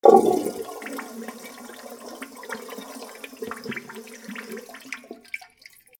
男子トイレ 水を流す
/ M｜他分類 / L05 ｜家具・収納・設備 / トイレ
『ゴポポ』